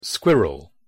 pronunciation_en_squirrel.mp3